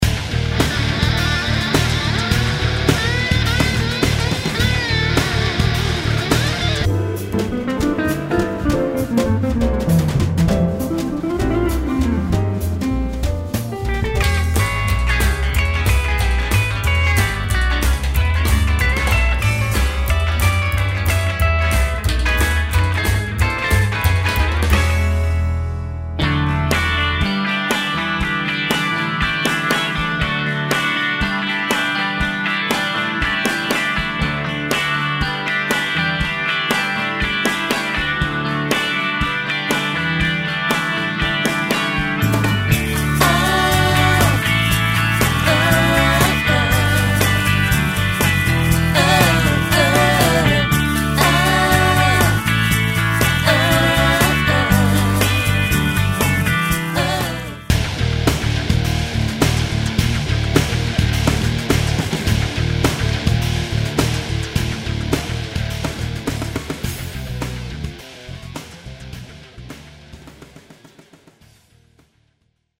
It can really twang, chug hard, be warm, do an ultimate jangle, evoke screaming demons.
small demo
with his prototype Synapse TranScale.
SynapseDemo.mp3